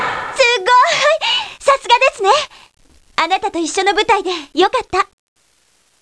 しかし、クリアできないときは得点によってモーリンのコメントが変わる。また、クリアしたときは被弾率によってコメントが変わる。